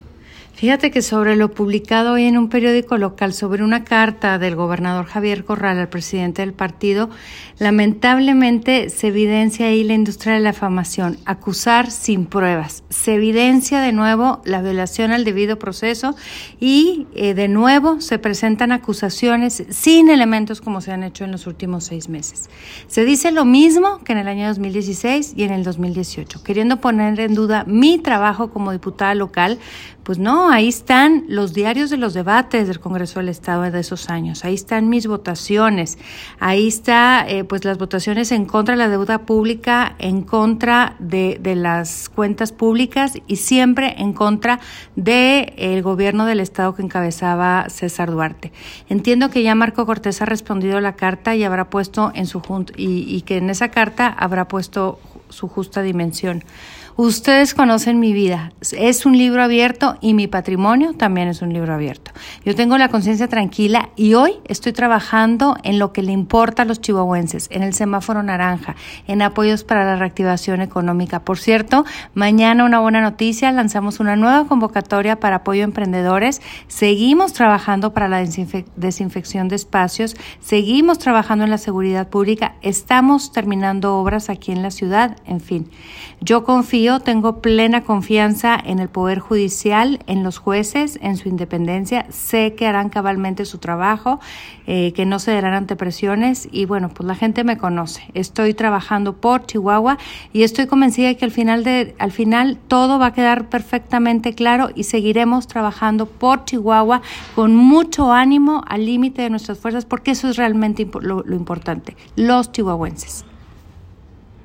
Audio-Alcaldesa.mp3